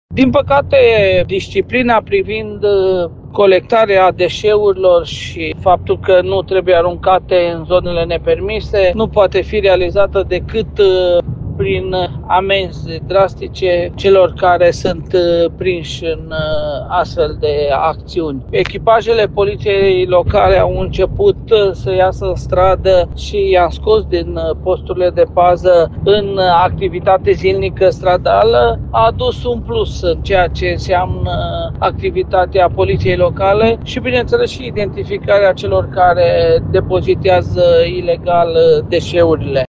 Primarul municipiului, Călin Dobra, spune că lipsa de disciplină în ceea ce privește colectarea deșeurilor poate fi corectată doar prin sancțiuni ferme.